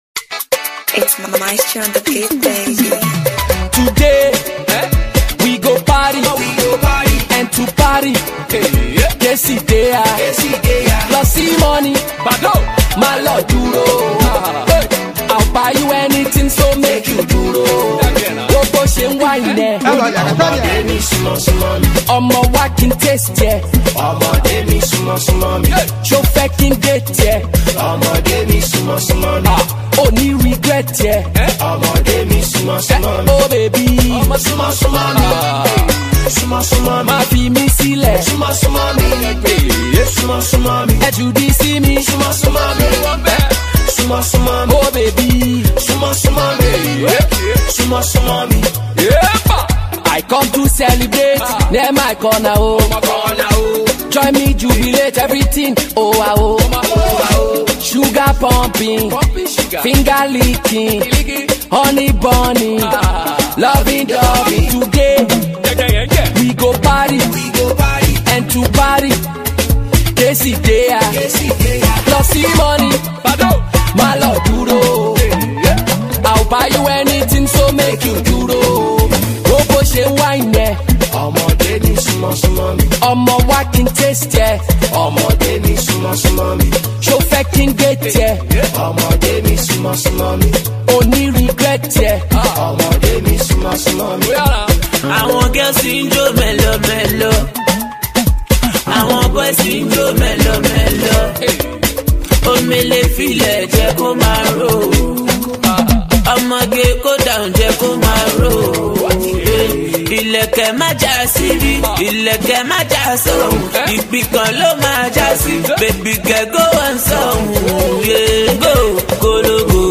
groovy track for the dance floor
high life tune